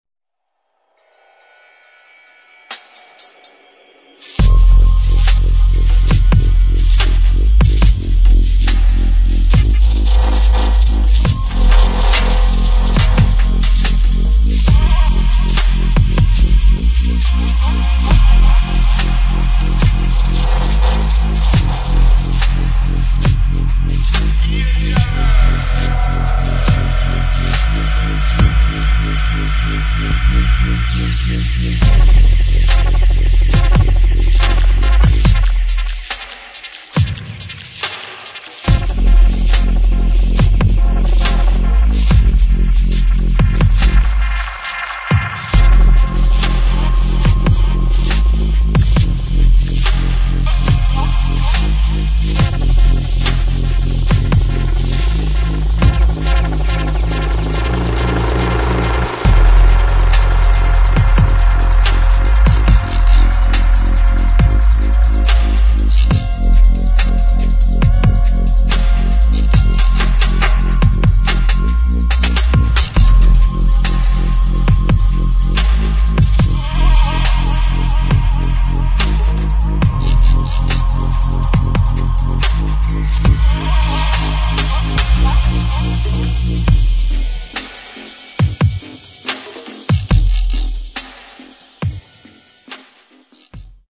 [ DRUM'N'BASS / DUBSTEP / BASS ]